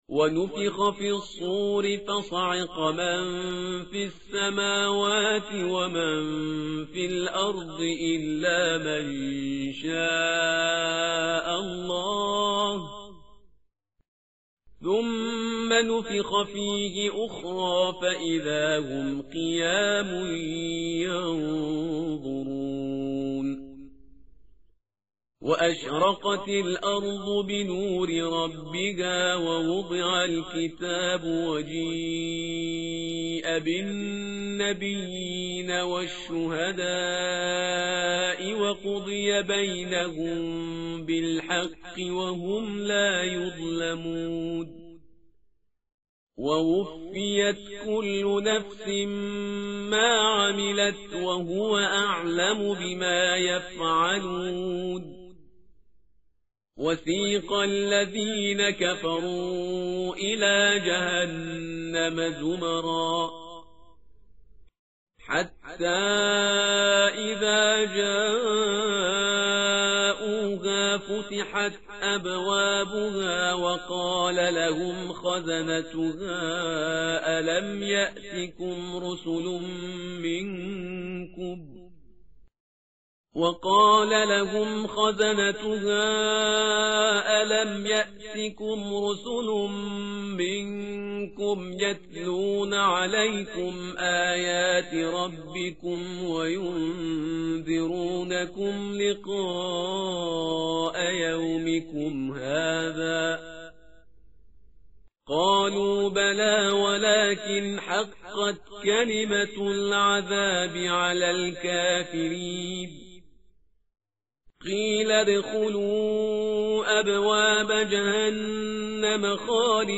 tartil_parhizgar_page_466.mp3